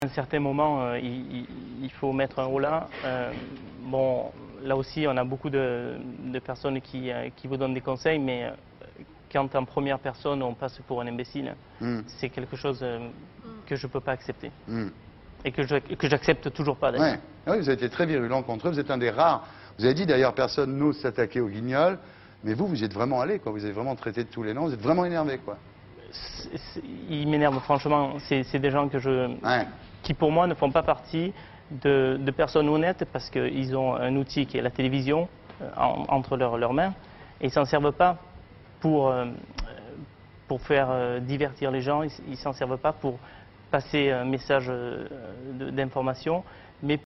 Je ne demande pas l’interviewer (Ardisson) qu'on entend au milieu mais bien l'autre qui est un sportif ;):